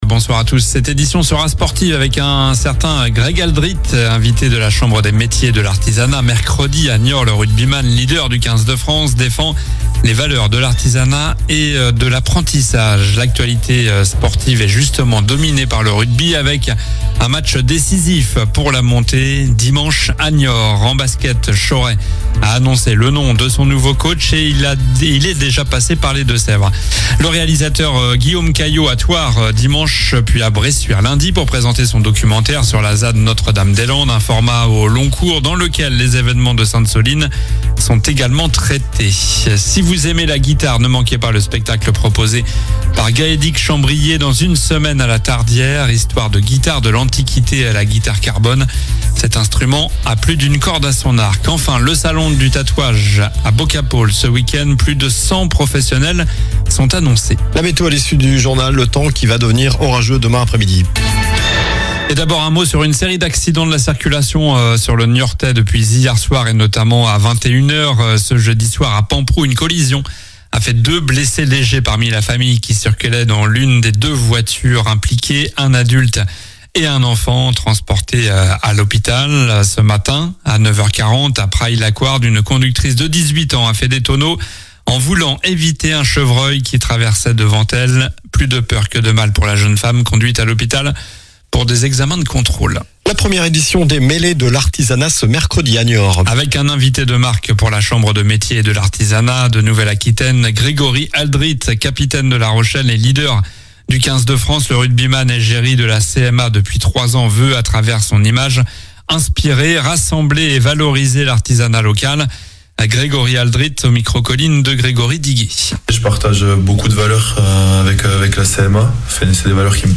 Journal du vendredi 9 mai (soir)